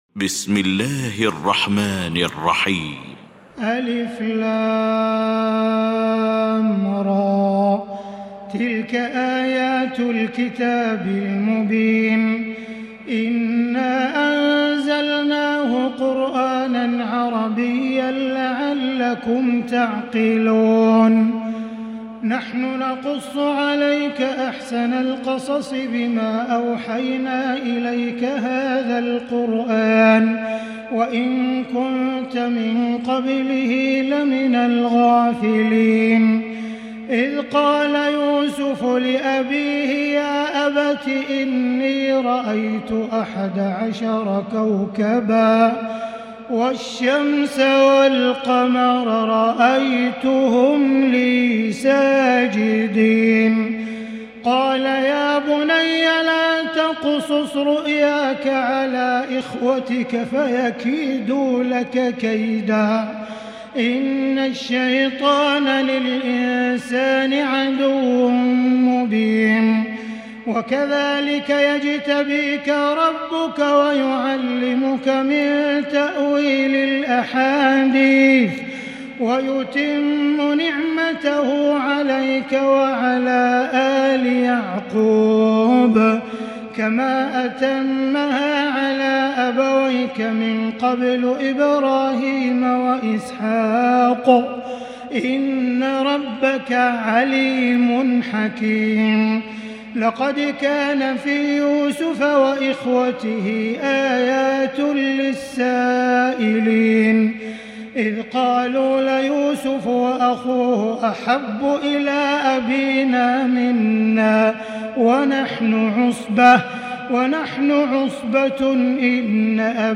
المكان: المسجد الحرام الشيخ: سعود الشريم سعود الشريم معالي الشيخ أ.د. عبدالرحمن بن عبدالعزيز السديس يوسف